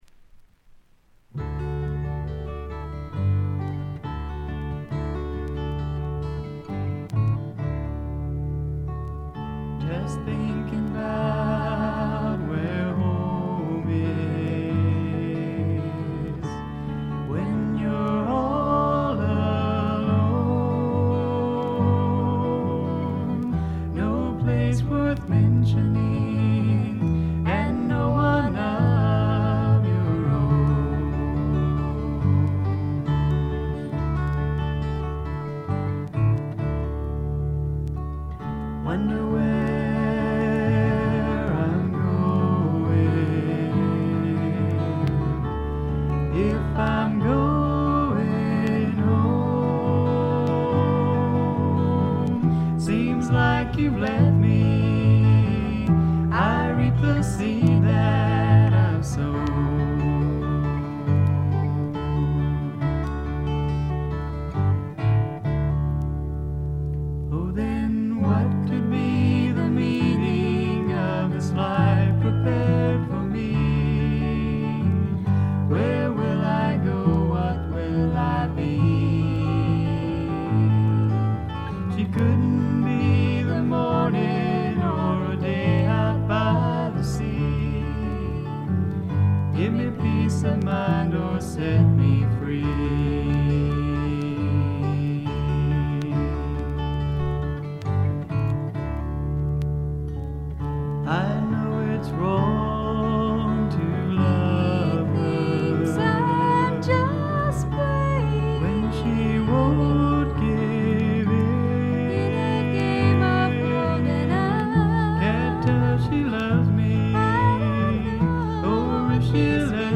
軽いバックグラウンドノイズ。
ローナーフォーク、ドリーミーフォークの逸品です。
試聴曲は現品からの取り込み音源です。